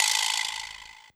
CLF Rattle.wav